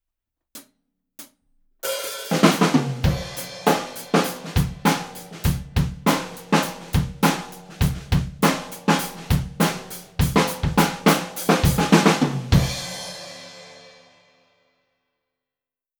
【サンプル音源あり】MXL V67G 一本のみでドラムレコーディング！
すべて、EQはしていません。
① アンビエンス　約２ｍ
約２ｍのところにマイキングしてみました。
キャラクターとしては、暗めな印象ですね！
あまりうるさくなりすぎずGOODです！